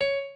pianoadrib1_23.ogg